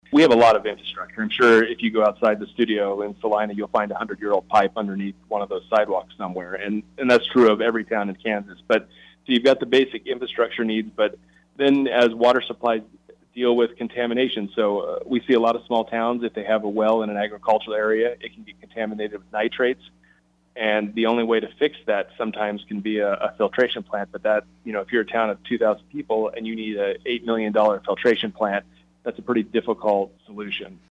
Titus joined in on the KSAL Morning News Extra with a look at the Water Task Force objectives Governor Kelly outlined earlier this year and says the job is about ensuring accountability in city, towns and country side.